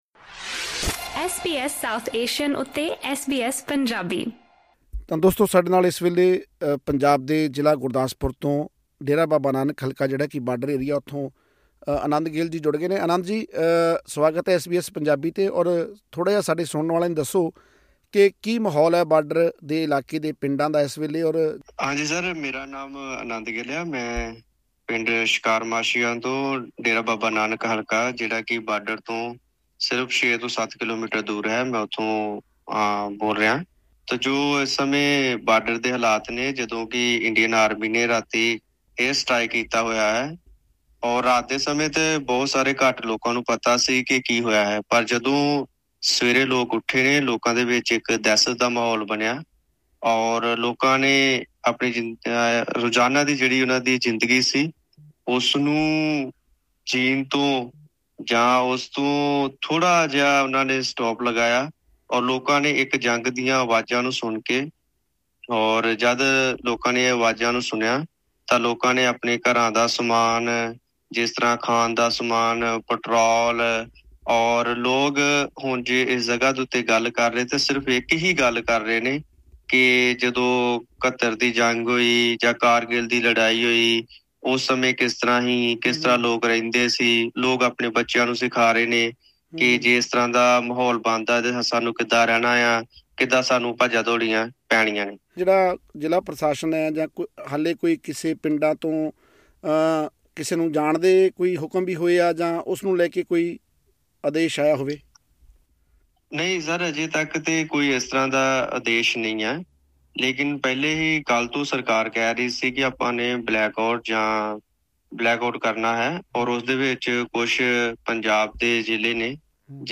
ਹੋਰ ਵੇਰਵੇ ਲਈ ਸੁਣੋ ਇਹ ਆਡੀਓ ਰਿਪੋਰਟ